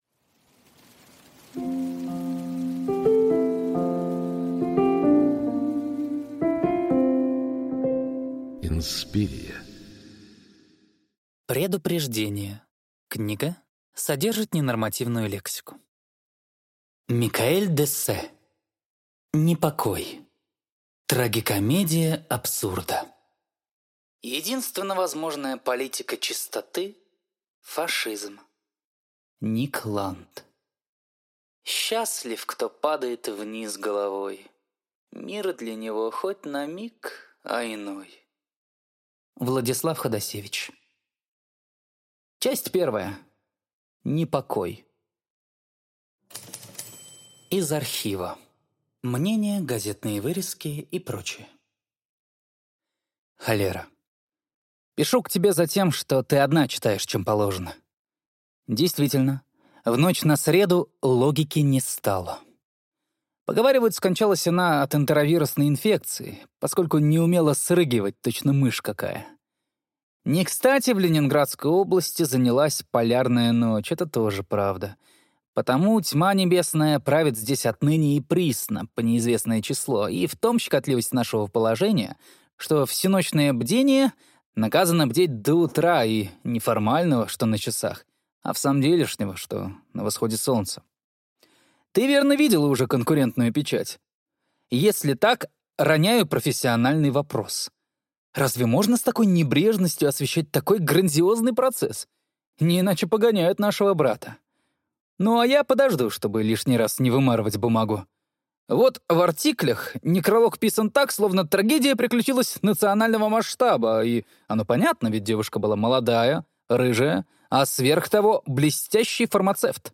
Аудиокнига Непокой | Библиотека аудиокниг